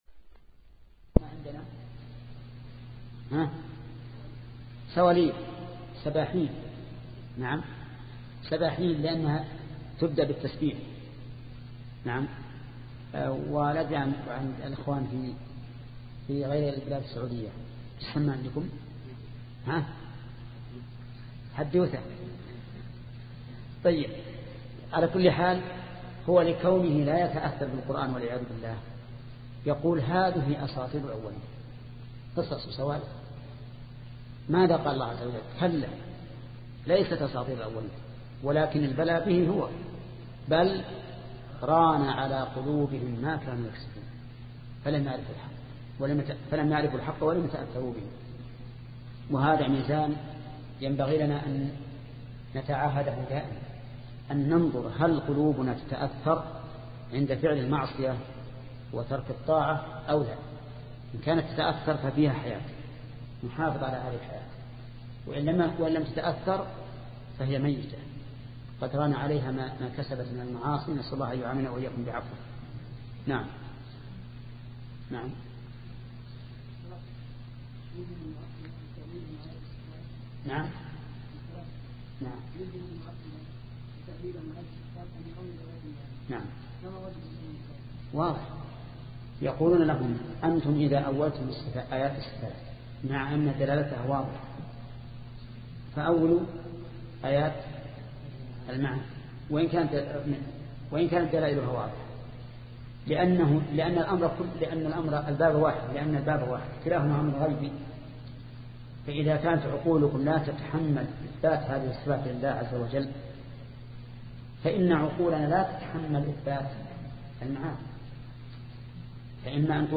شبكة المعرفة الإسلامية | الدروس | التعليق على القصيدة النونية 40 |محمد بن صالح العثيمين